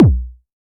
RDM_TapeA_SY1-Kick02.wav